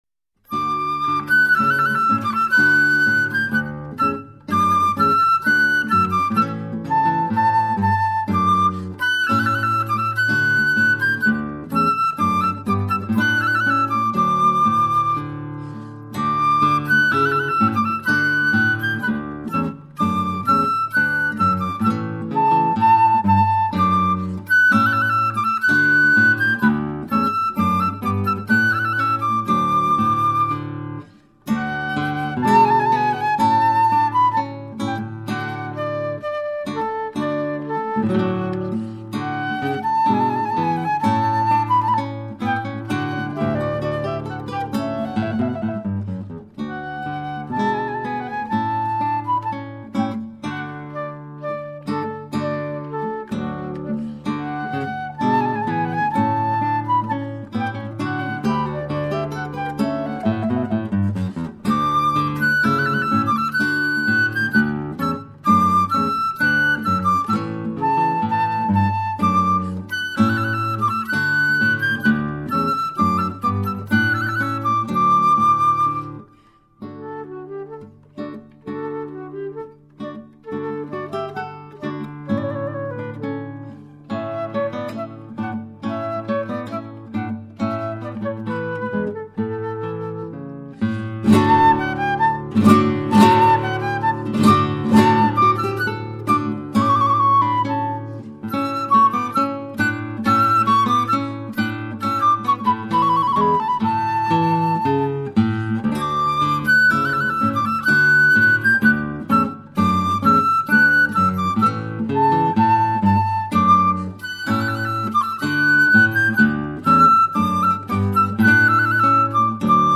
Group: Instrumental